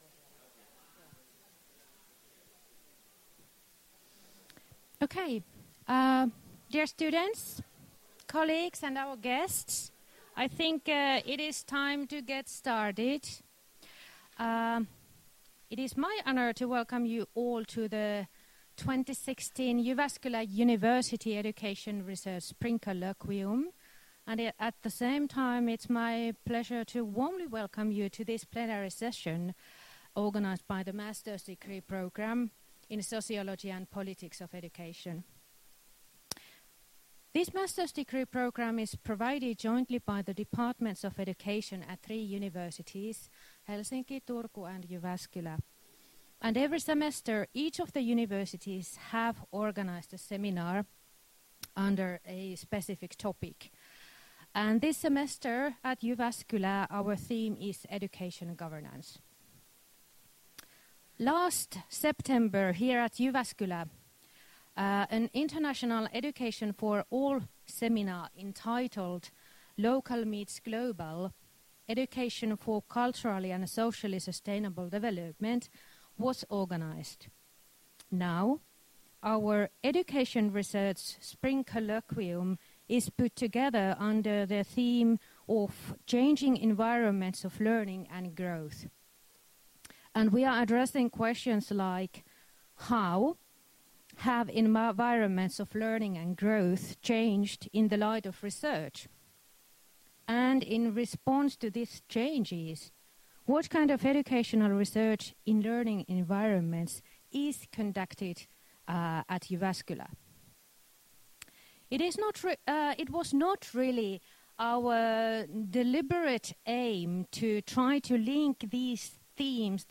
Keynotes / Education Policy and Sociology Programme / JYU Education Research Colloquium 13.4.2016